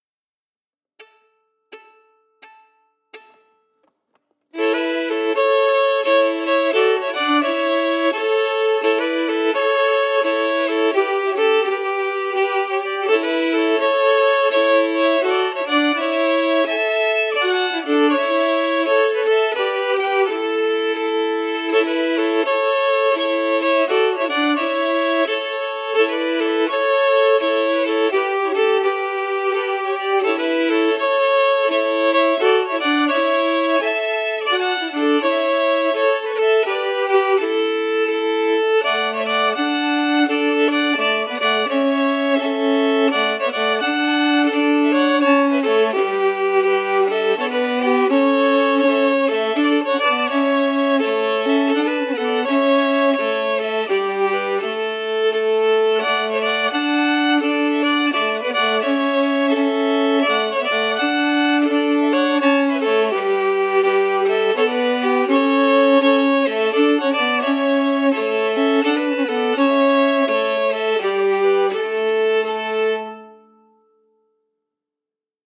Key: A Mixolydian
Form: Retreat March (9/8 time)
Harmony emphasis